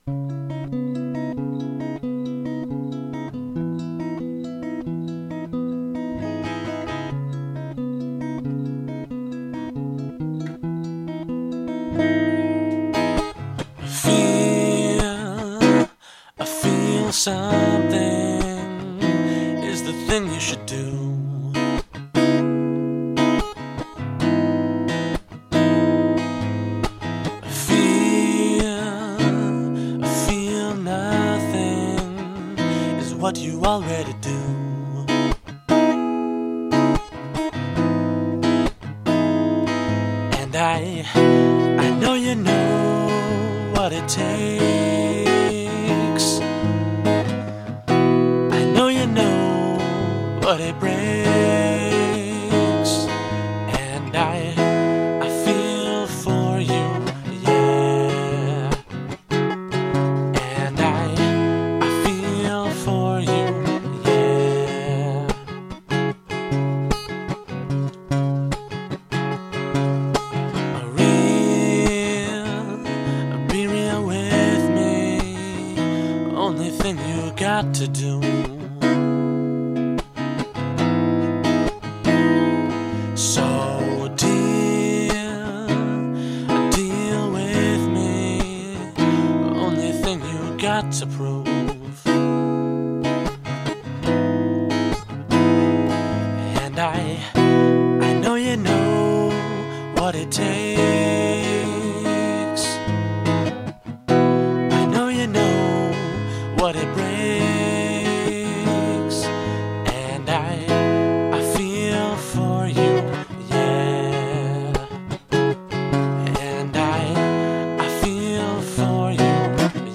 This is a song I wrote a few days ago called Feel. I'm playing and singing at the same time in this recording and overdubbed the solo.